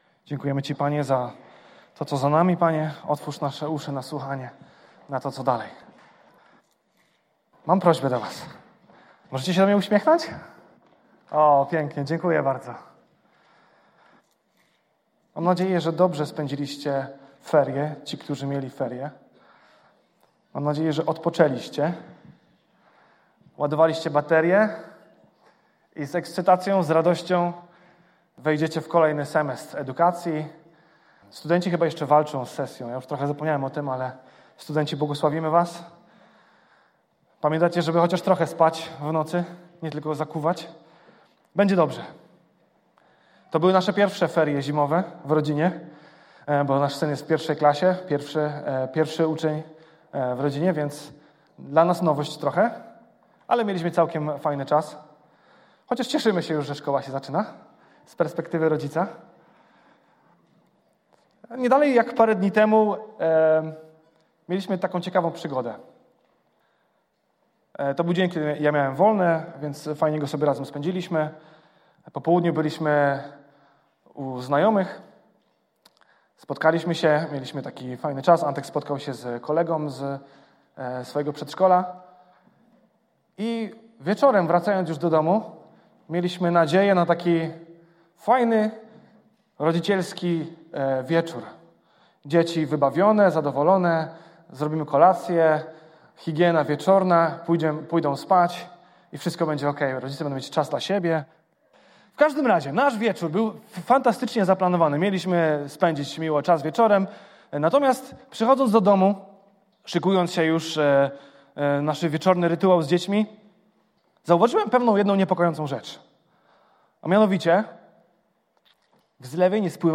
Kościół Zielonoświątkowy – Zbór "Betlejem" w Krakowie
Pytania do przemyślenia po kazaniu: